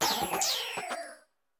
Cri de Cryodo dans Pokémon Écarlate et Violet.